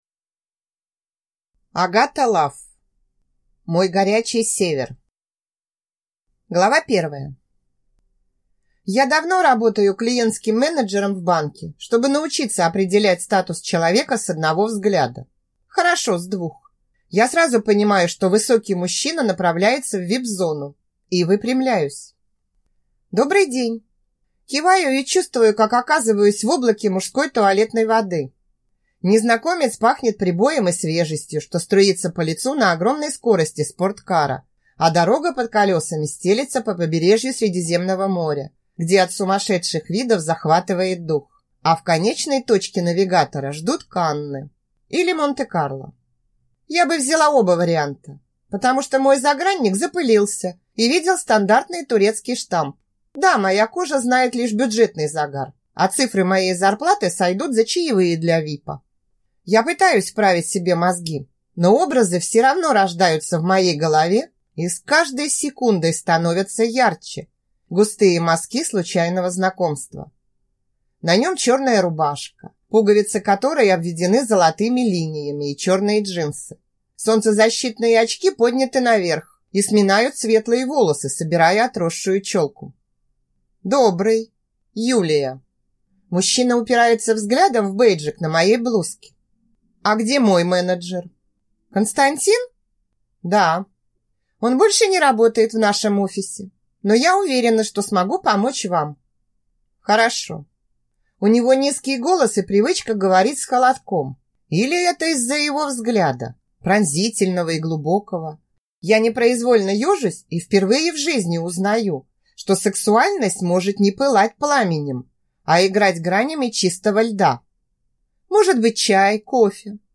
Аудиокнига Мой горячий Север | Библиотека аудиокниг